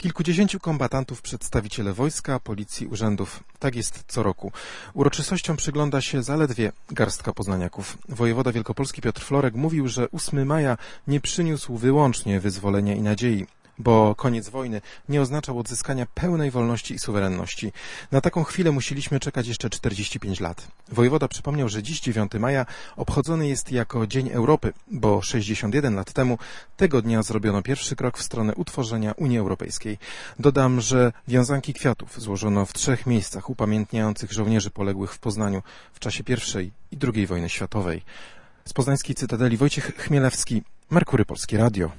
7ldcm9q24kkrh6o_zakonczenie_wojny_relacja.mp3